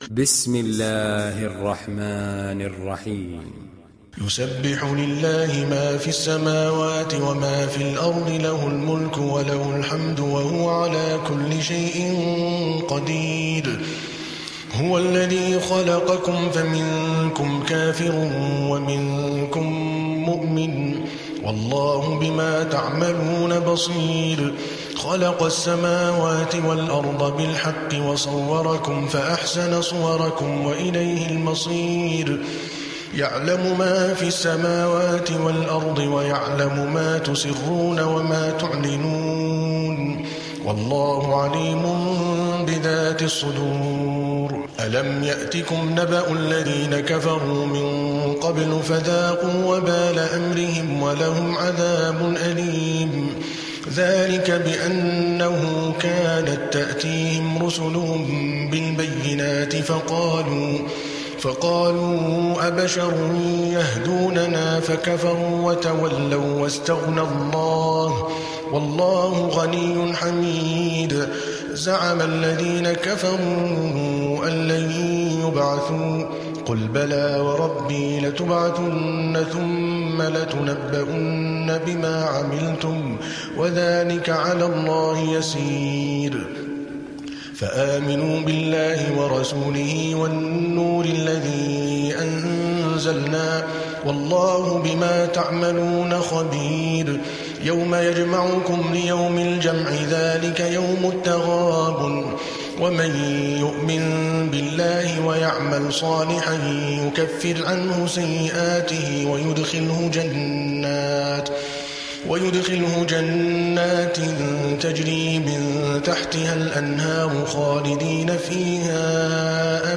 Surat At Taghabun Download mp3 Adel Al Kalbani Riwayat Hafs dari Asim, Download Quran dan mendengarkan mp3 tautan langsung penuh